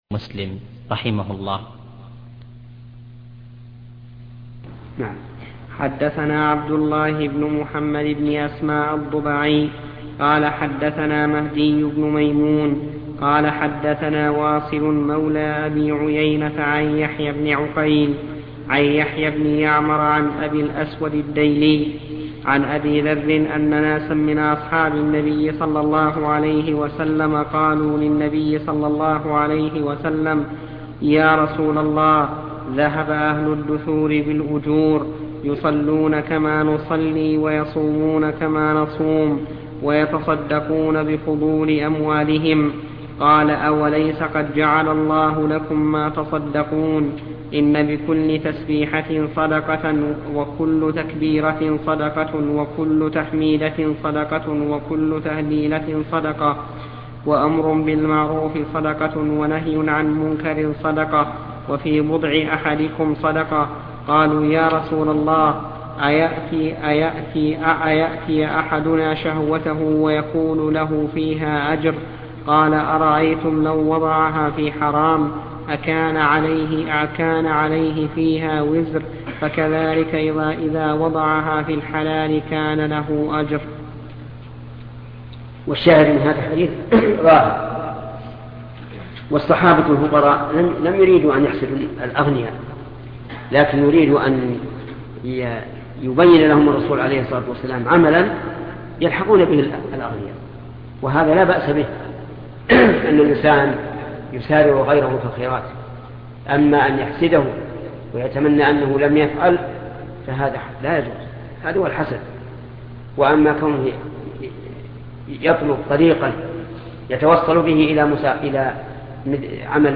صحيح مسلم شرح الشيخ محمد بن صالح العثيمين الدرس 161